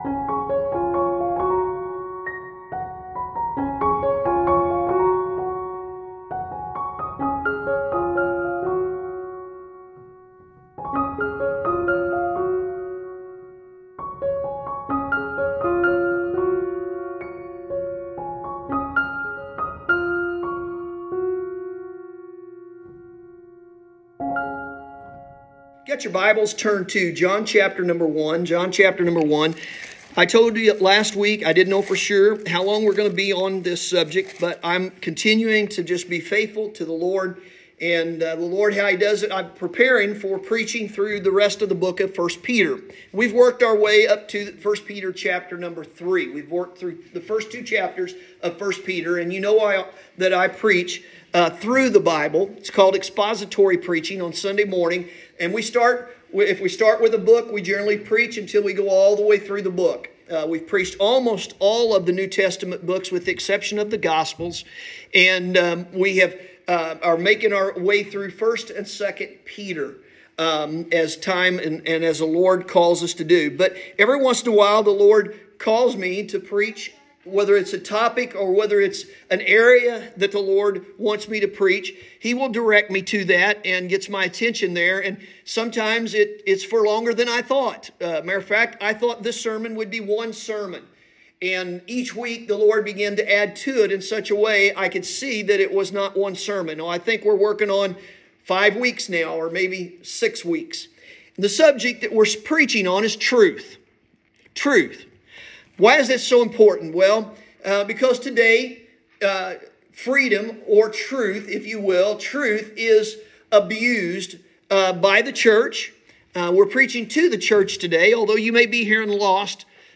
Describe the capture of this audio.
Sunday Morning – February 12, 2023